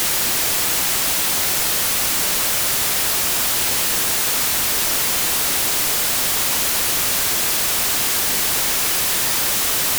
front_noise_ambiX.wav